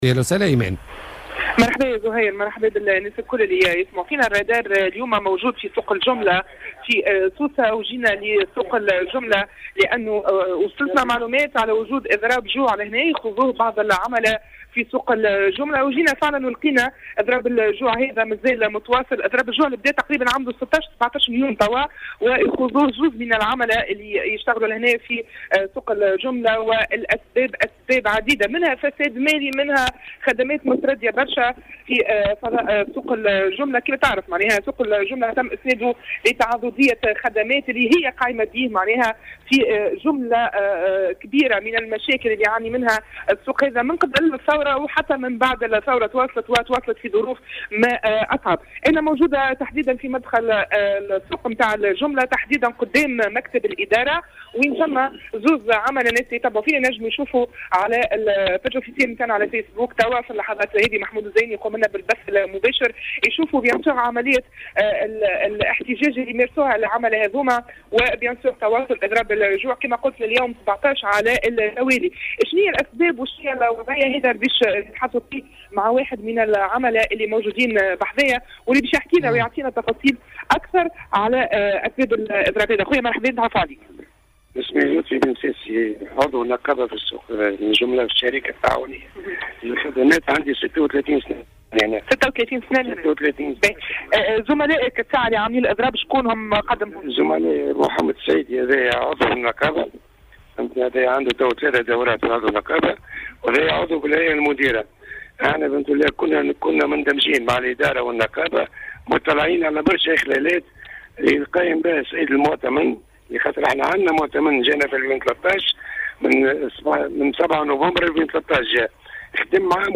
تنقّل فريق الرادار اليوم الأربعاء الى سوق الجملة بسوسة أين عاين دخول عاملين في اضراب جوع.